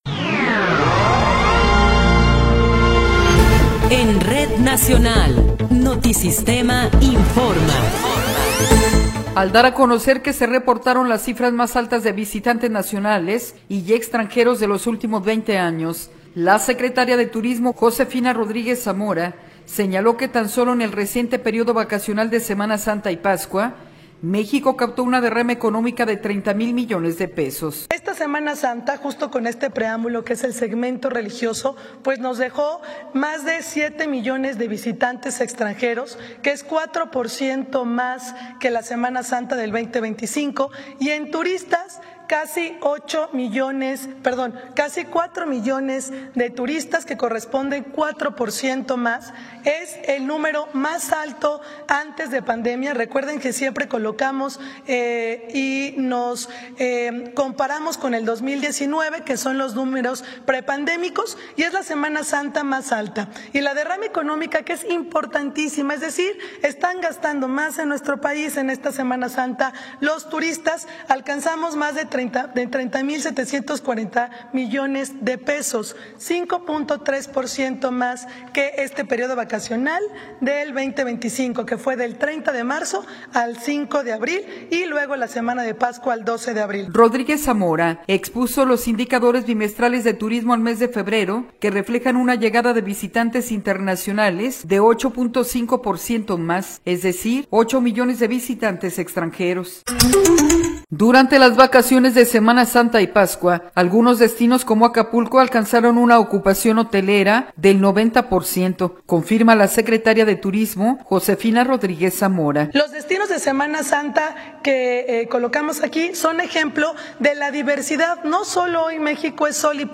Noticiero 11 hrs. – 26 de Abril de 2026
Resumen informativo Notisistema, la mejor y más completa información cada hora en la hora.